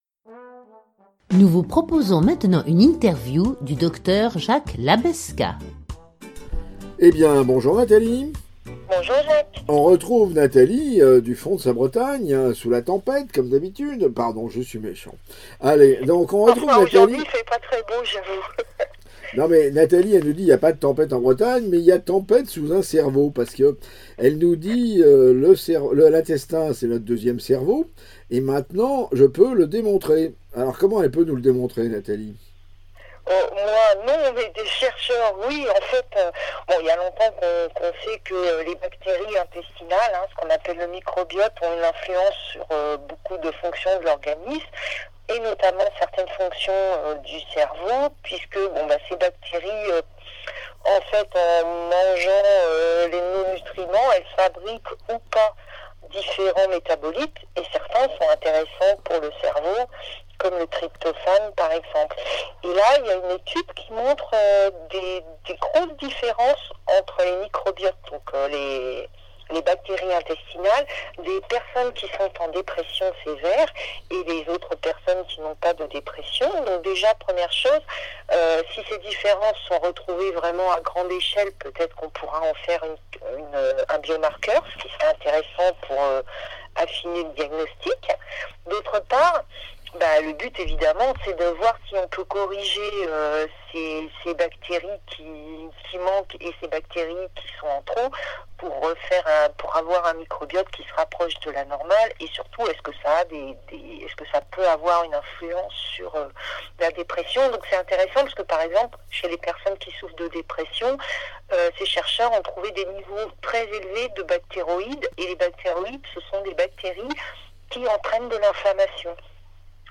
Un entretien